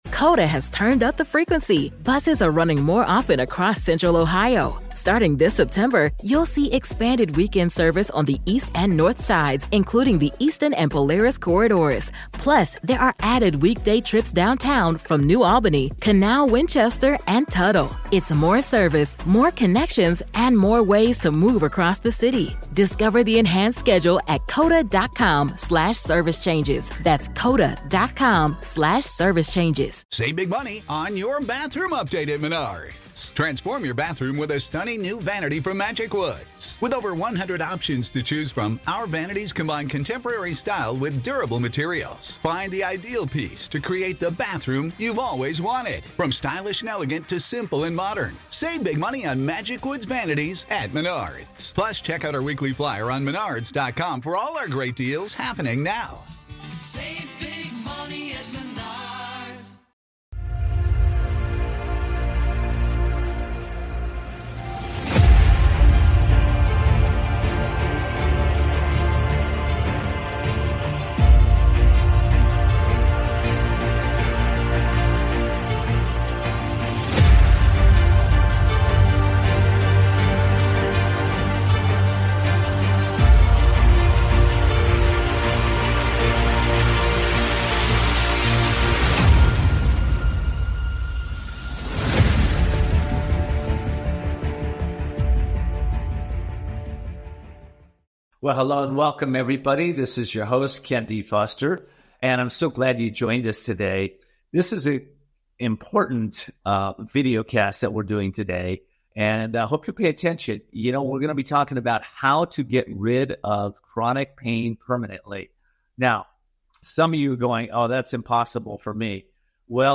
Voices of Courage is a power packed radio, podcast and TV show, where audiences engage to grow their business and transform their life’s. The 60-minute weekly shows are provocative, entertaining and transformational.